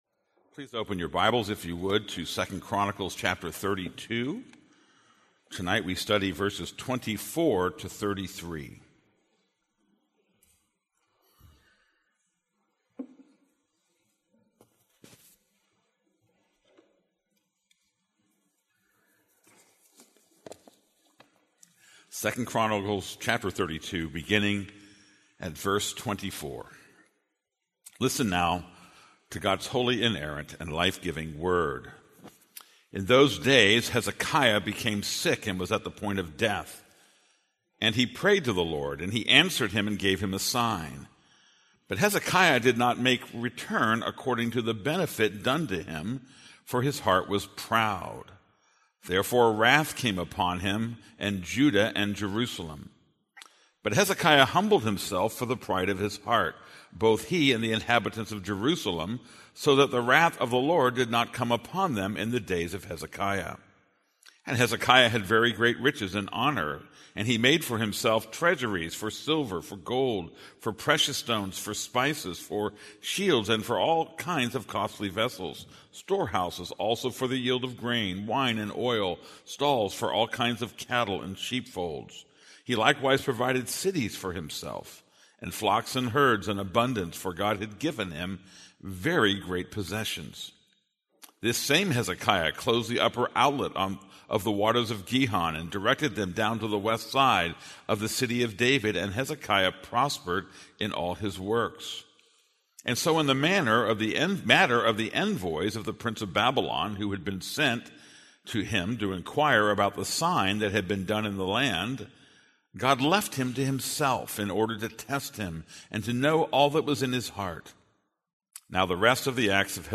This is a sermon on 2 Chronicles 32:24-33.